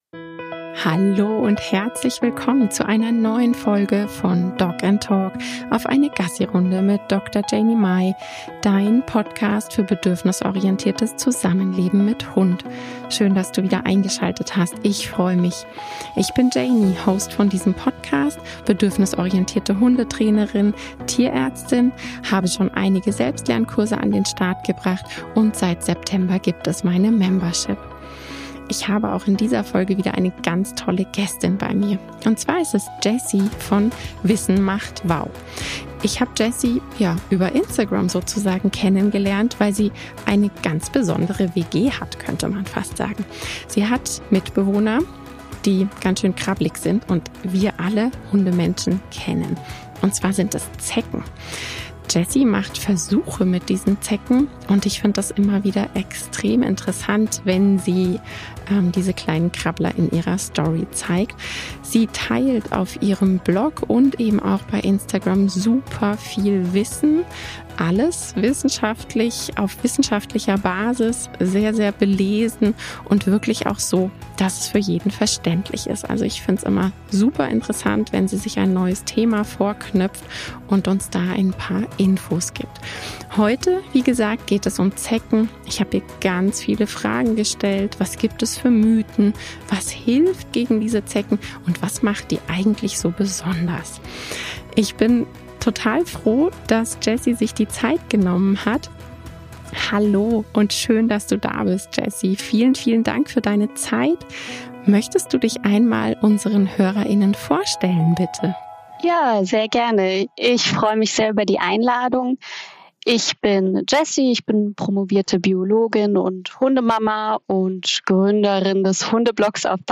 Das Thema Zecken und damit die Abwehr dieser, geht jeden Hundemenschen etwas an. Das Besondere: meine Interviewpartnerin hat spannende Mitbewohner